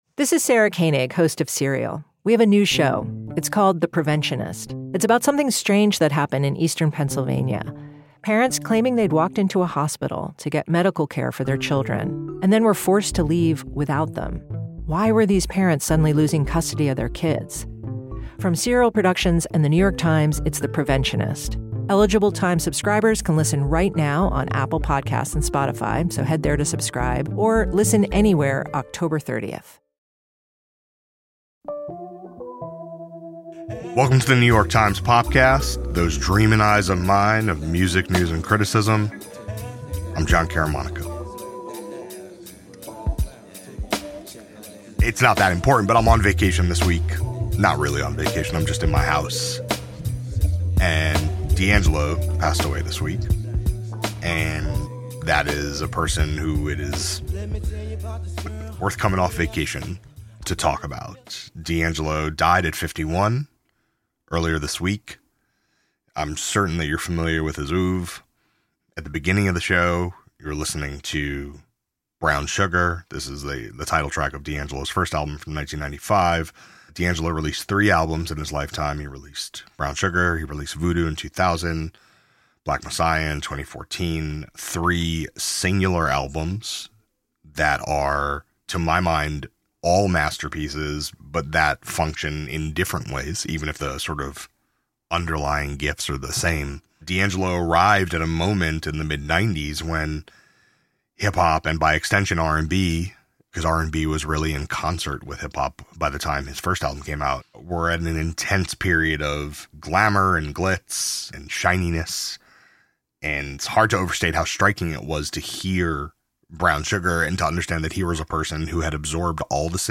A conversation about the casual virtuosity of D’Angelo’s too-brief career with a pair of journalists who each interviewed him twice.